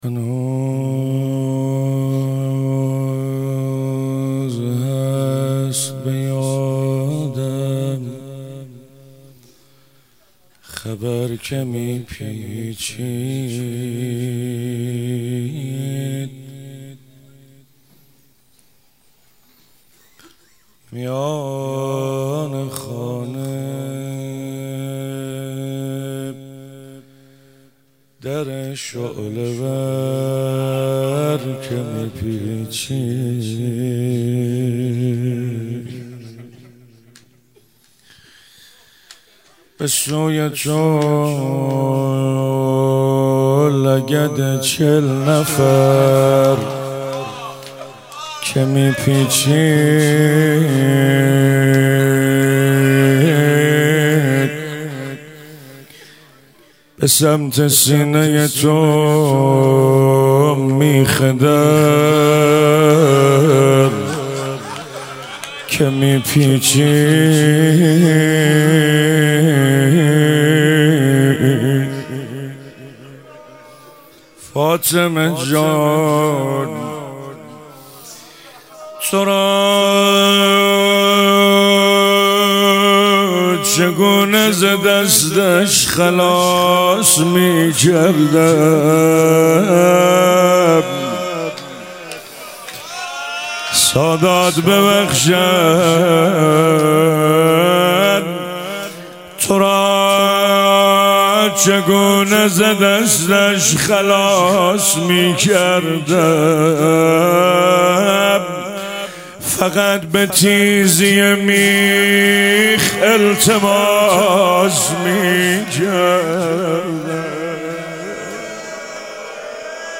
18 بهمن 96 - تهران - روضه - هنوز هست به یادم خبر